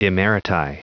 Prononciation du mot emeriti en anglais (fichier audio)
Prononciation du mot : emeriti